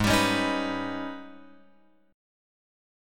G# Minor Major 11th